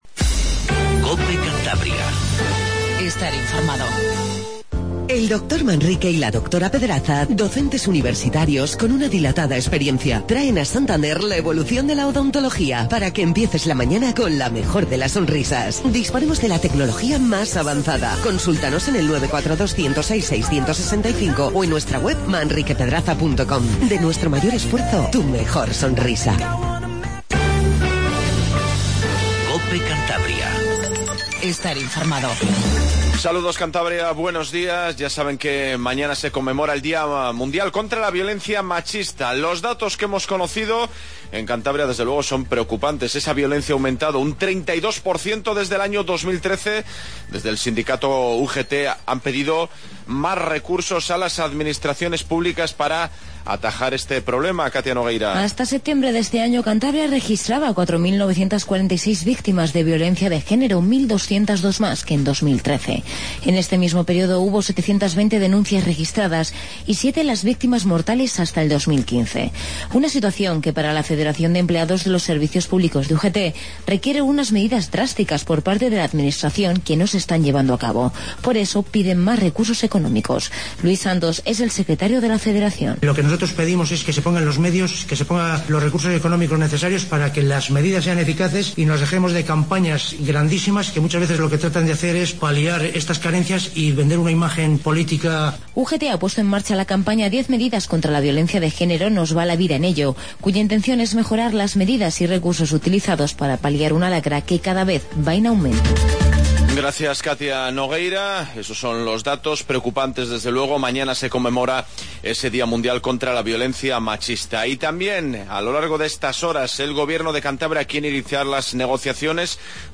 INFORMATIVO MATINAL 08:20